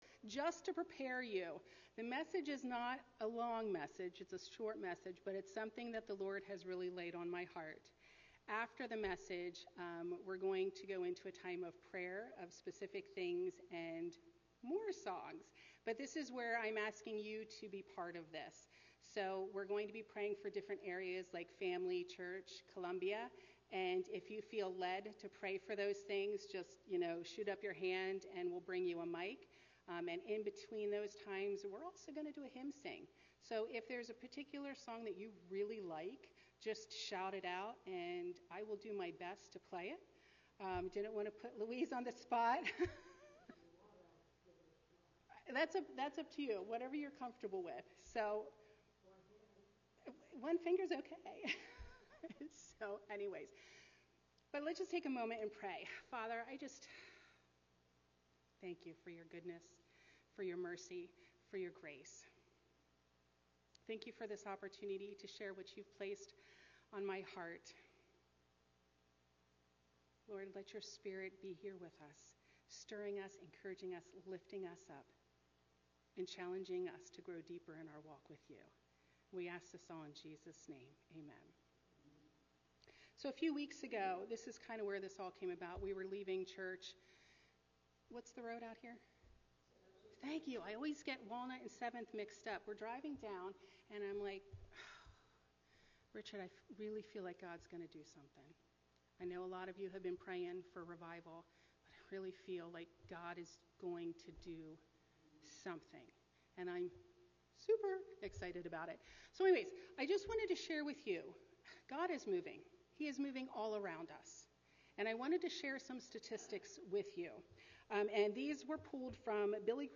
Sermons | Columbia Church of God